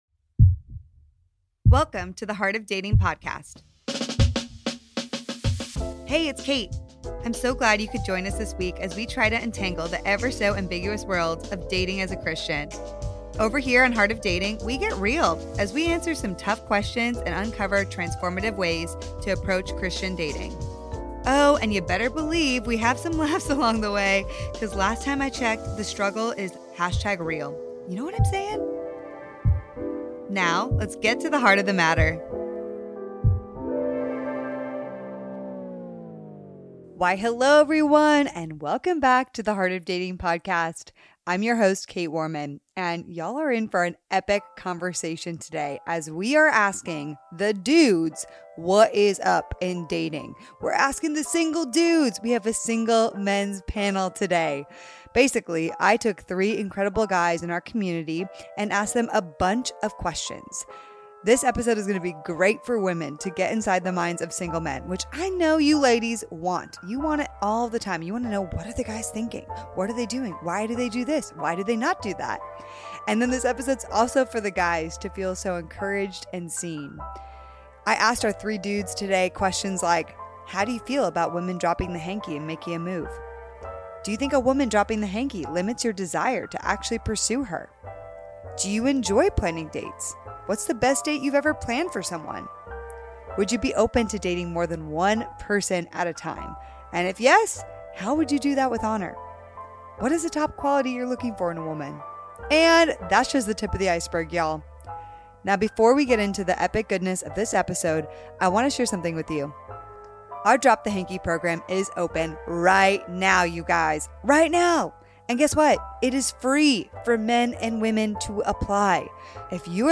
Single Men's Roundtable